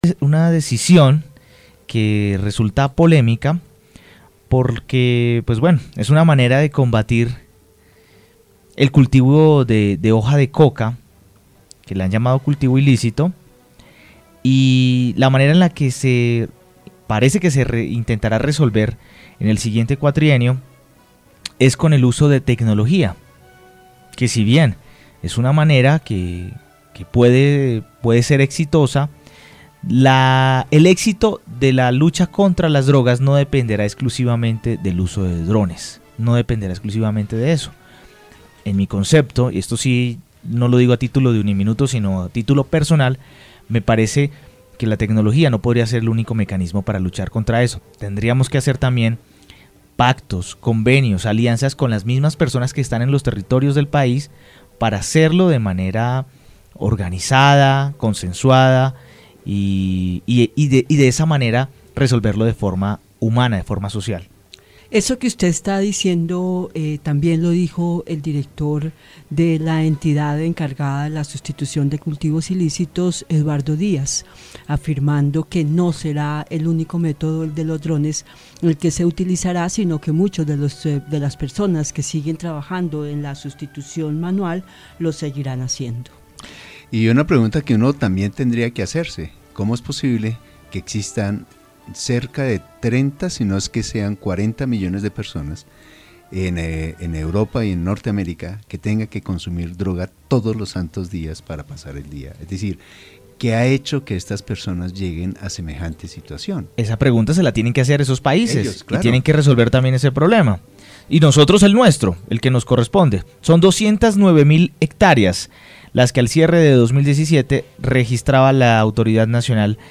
experto en drones, habló con el equipo de Aquí y Ahora de UNIMINUTO Radio acerca de los pros y contras de este escenario en la lucha contra el narcotráfico en Colombia.